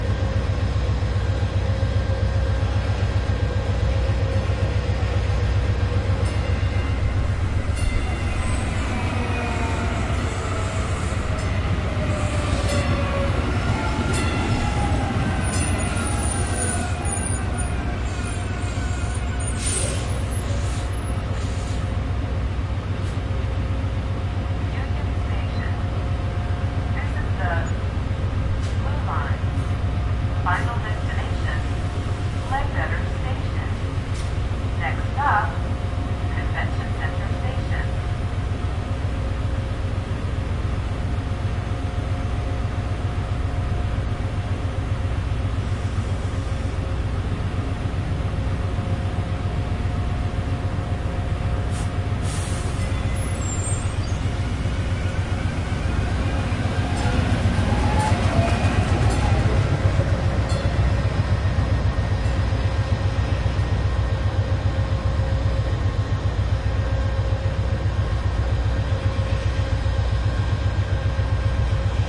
贝尔法斯特中央铁路站
包括一个滚动的行李箱，各种车站公告，铁路发动机空转、加速、减速到空转的白噪声，一些哔哔声，释放压缩空气和其他各种火车的声音，一两个哨子，以及一个壮观的本地火车出发，正好经过我的麦克风（没有剪掉红色）。
标签： 宣布 公告 到达 到达 唠叨 离开 离开 发动机 现场录音 拟音 闲置 噪声 铁路 铁路 REV revvin克 车站 列车 车轮
声道立体声